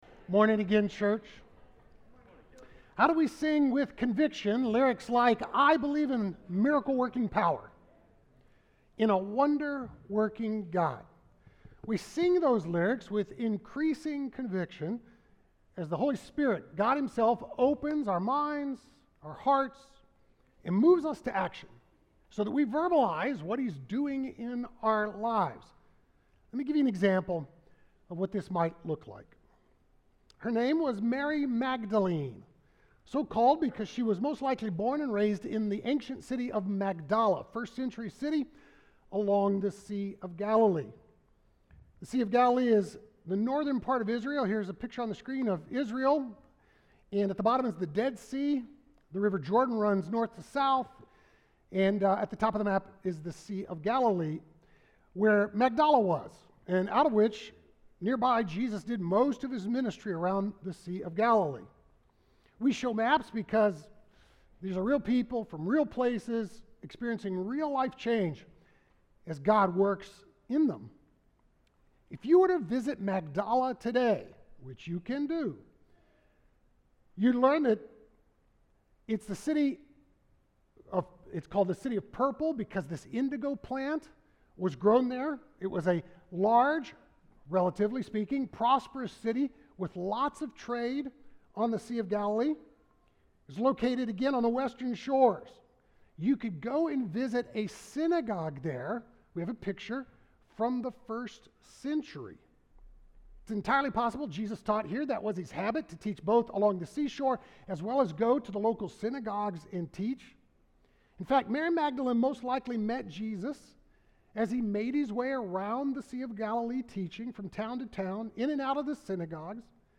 Sermons from Glen Ellyn Bible Church
Sermons